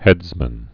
(hĕdzmən)